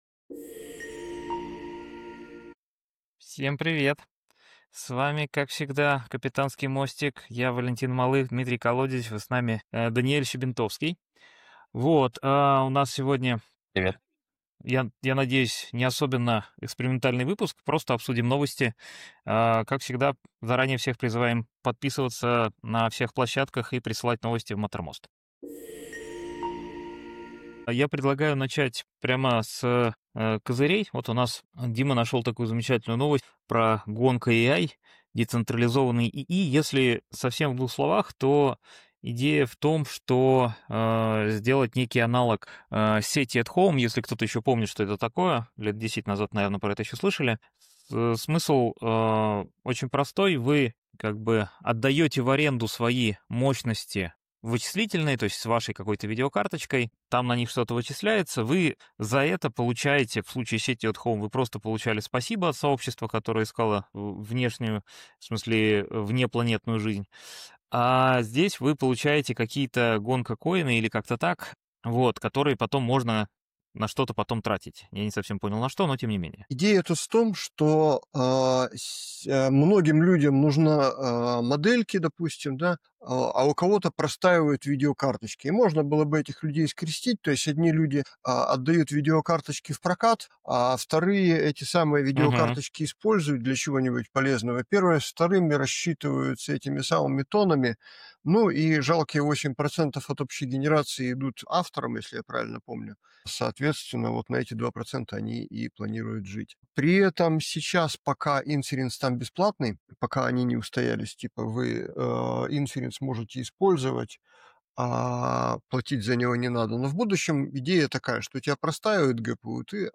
Участники делятся мнениями о перспективах и проблемах, связанных с новыми технологиями, а также о будущем ИИ, акцентируя внимание на моделях мира и их значении для развития AGI.